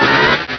Cri de Papinox dans Pokémon Rubis et Saphir.
Cri_0269_RS.ogg